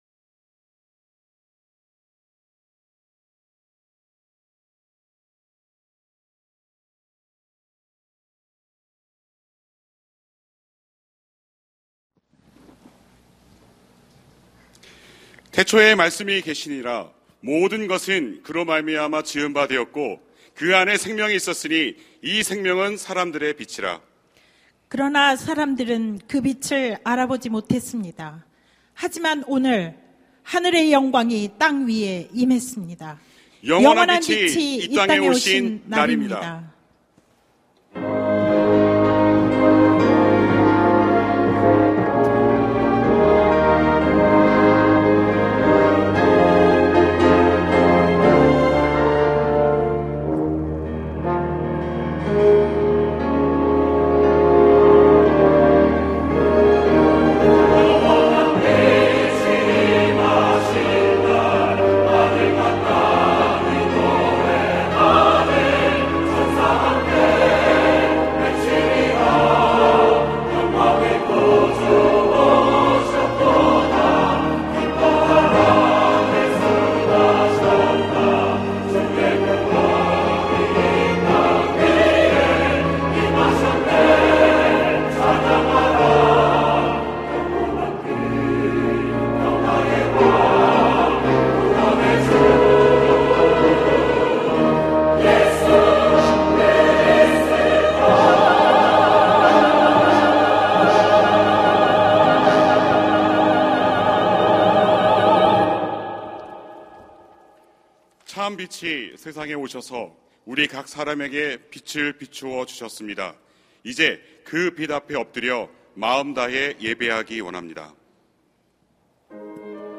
성탄절 칸타타